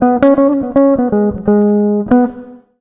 160JAZZ  9.wav